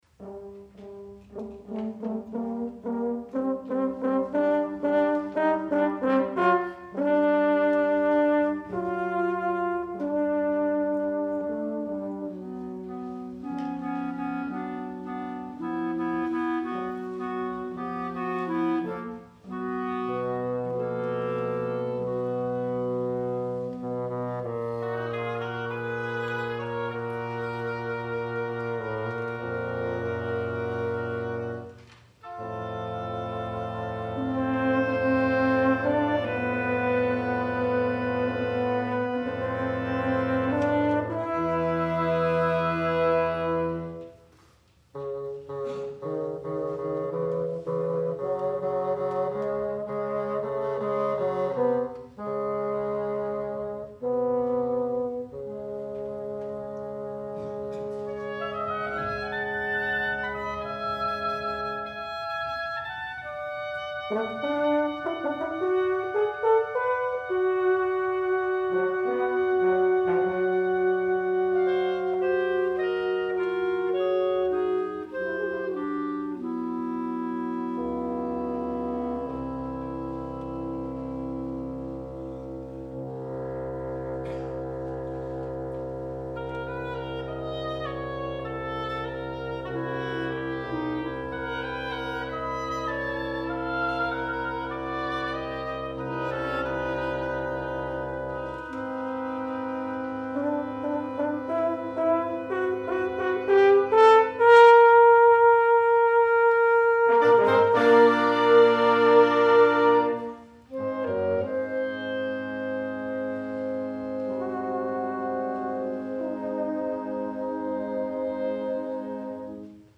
Wind Octet) Winds/Chamber 2008